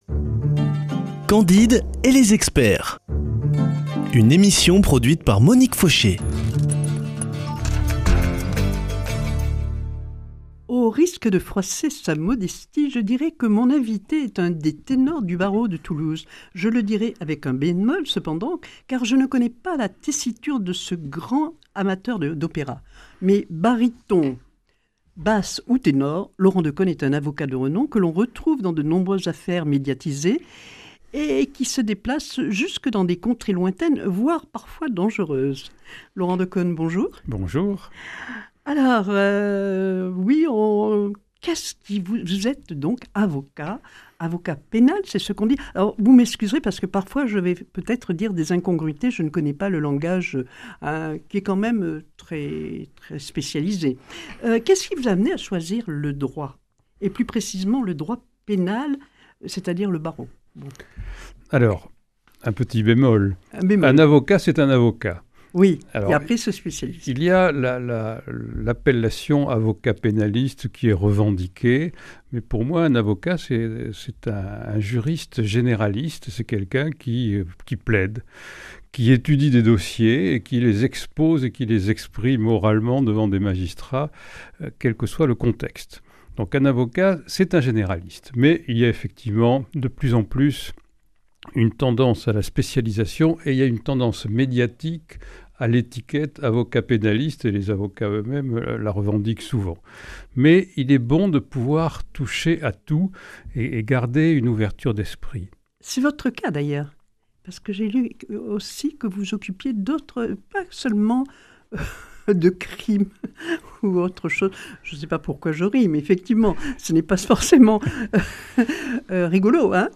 [ Rediffusion ]